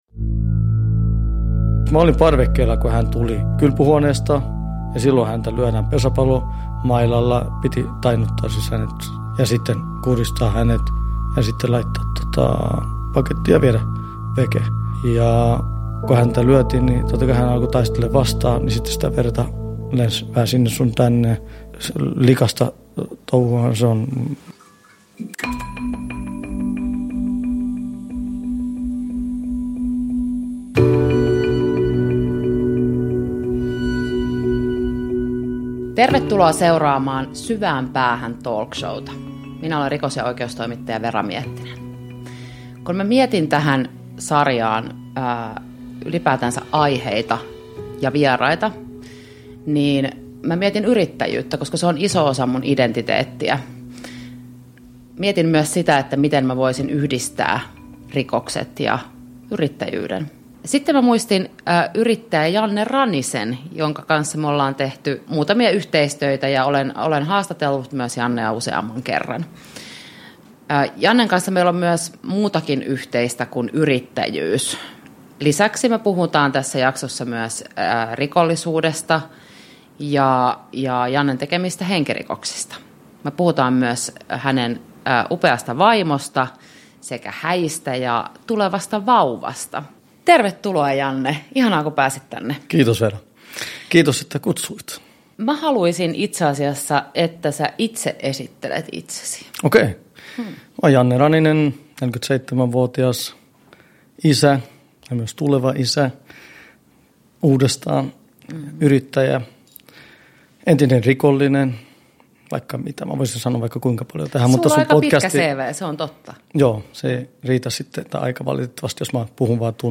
Syvään päähän – Ljudbok